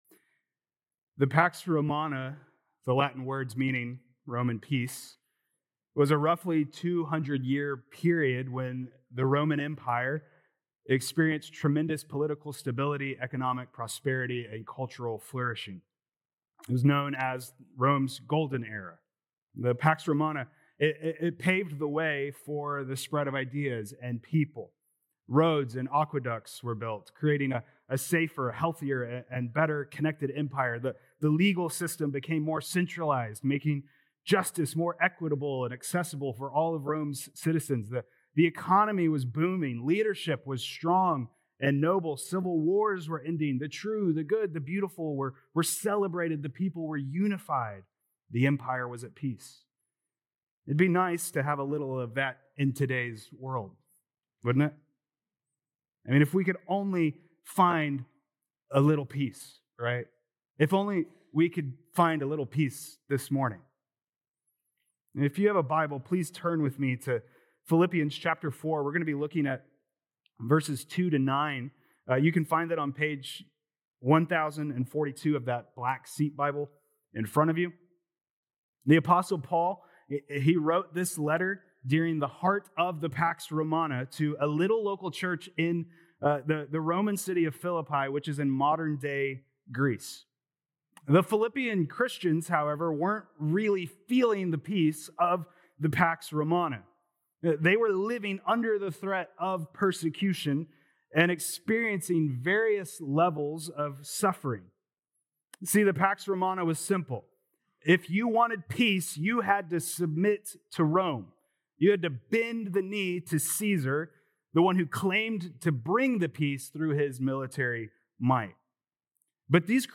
Oct 5th Sermon | Philippians 4:2-9